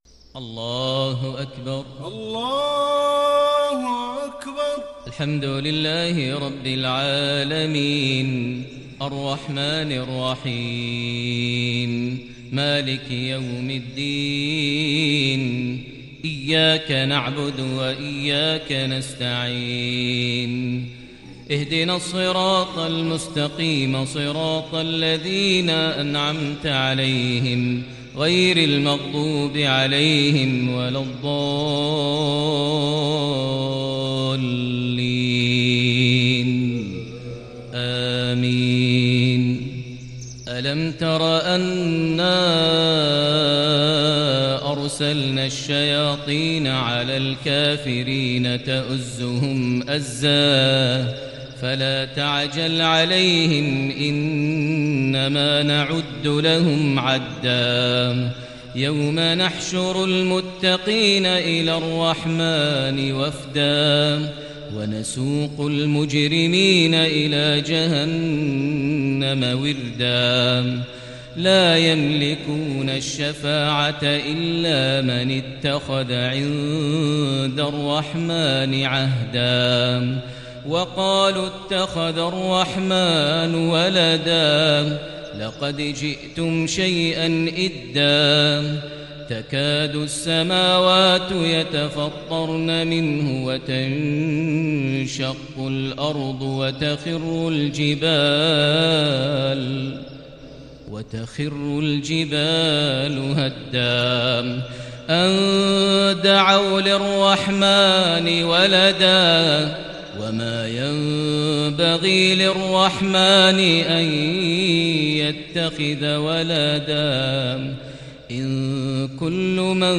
صلاة المغرب للشيخ ماهر المعيقلي 17 رجب 1441 هـ
تِلَاوَات الْحَرَمَيْن .